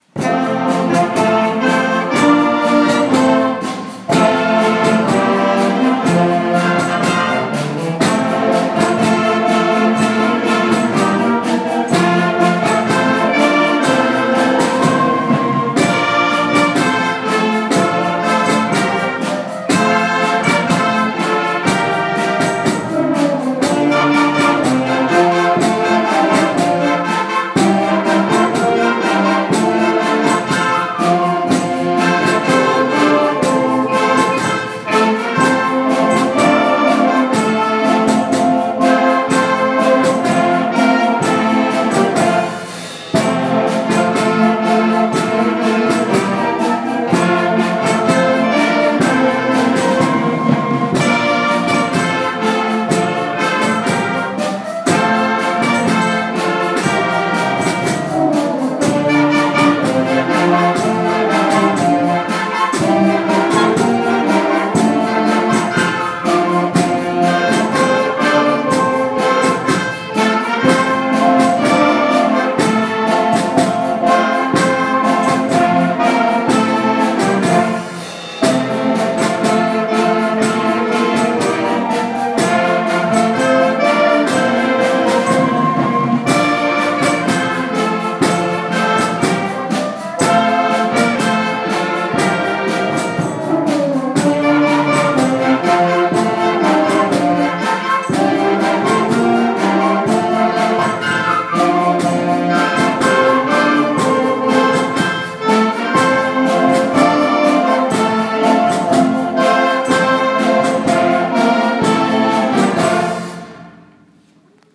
館山小校歌を公開録音（音楽部による演奏）しました。